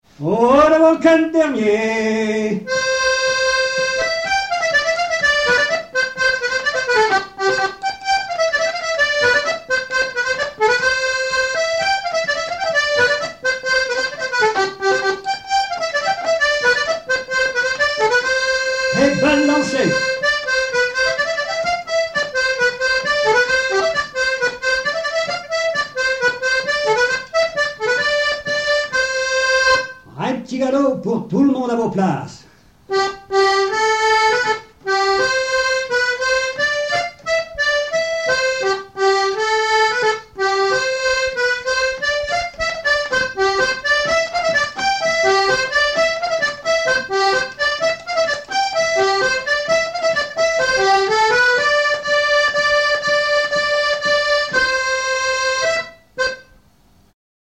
danse : quadrille
Pièce musicale inédite